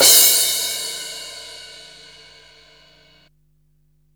CRASH06   -R.wav